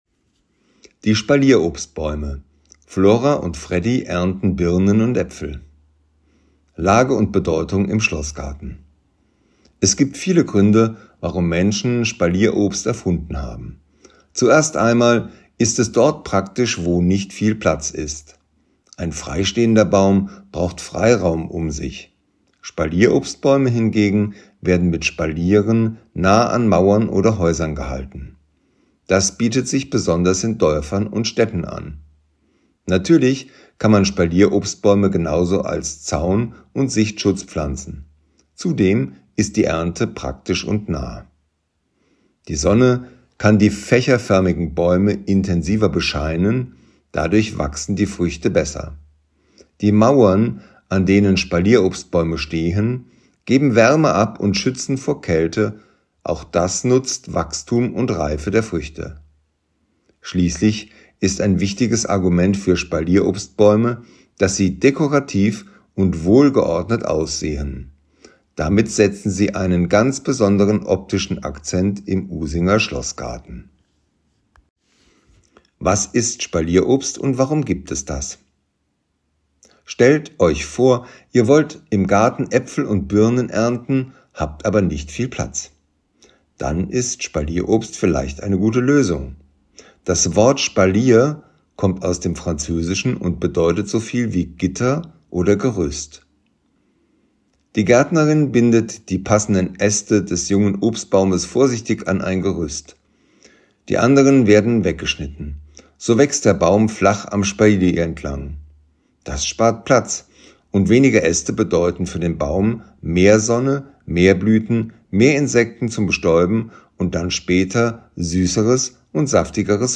Wer nicht alle Schilder an den Stationen im Schlossgarten lesen möchte oder kann, kann sie sich hier einfach vorlesen lassen.